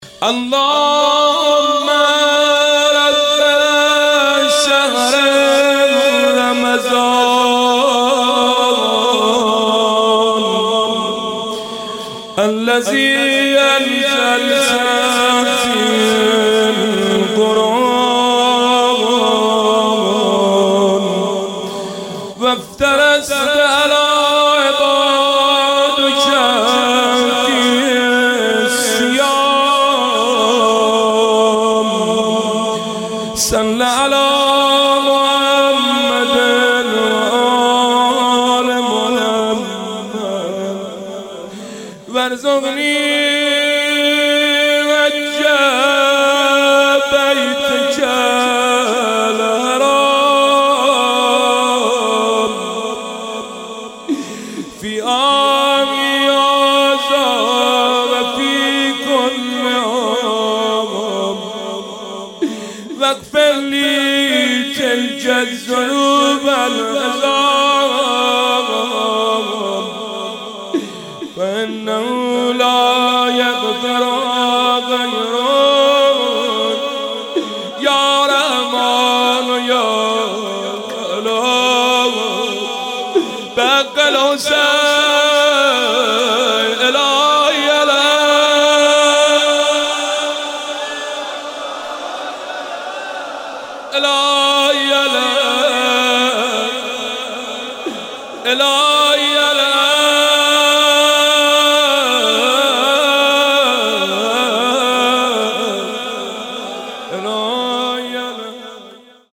مناسبت : شب نوزدهم رمضان - شب قدر اول
قالب : مناجات